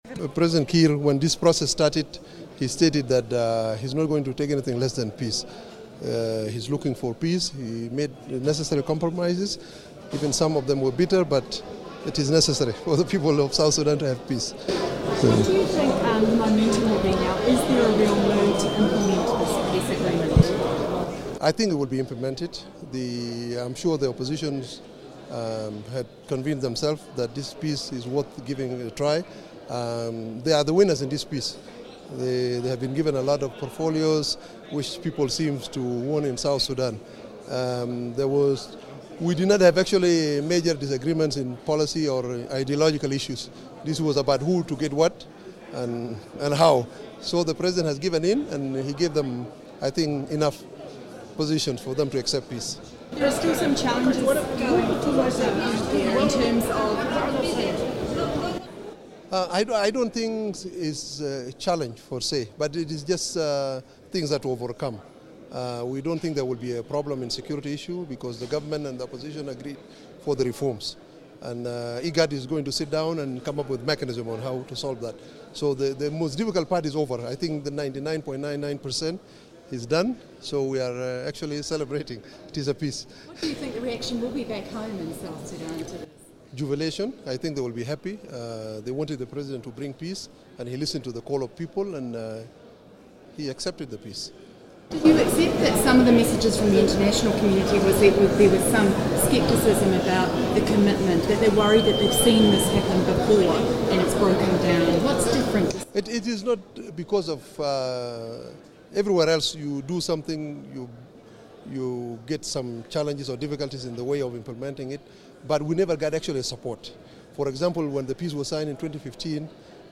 in Addis-Ababa.